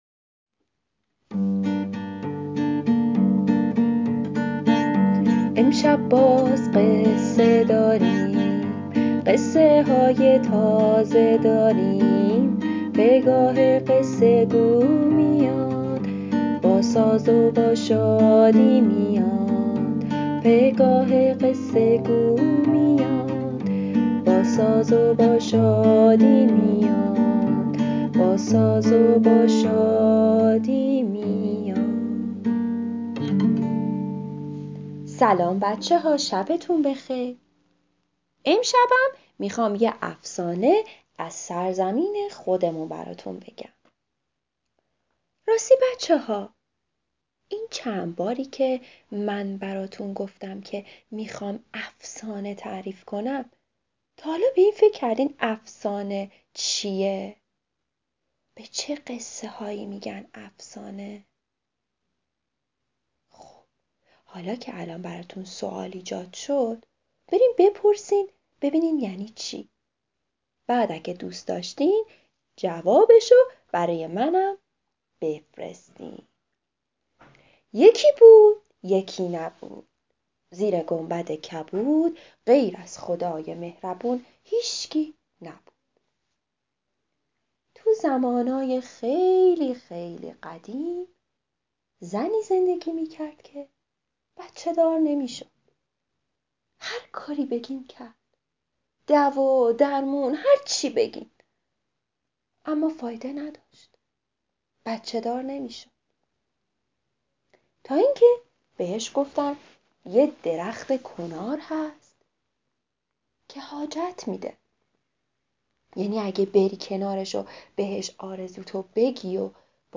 قصه صوتی کودکان دیدگاه شما 1,120 بازدید